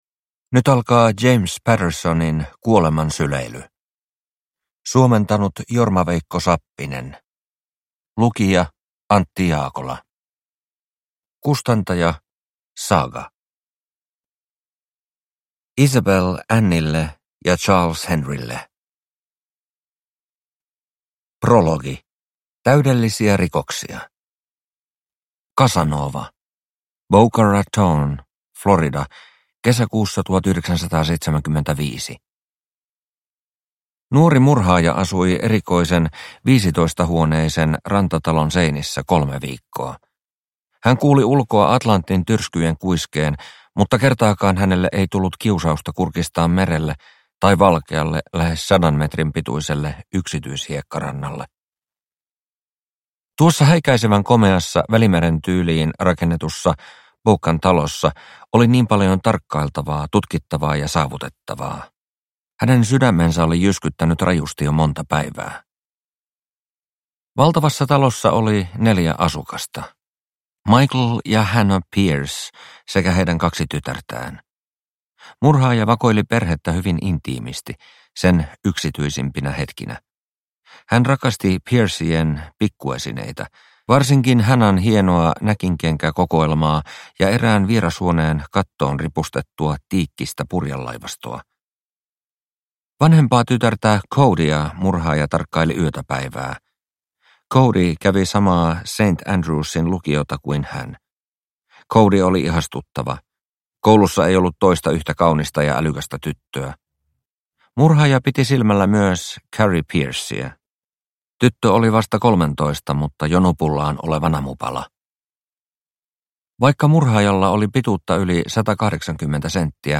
Kuoleman syleily – Ljudbok – Laddas ner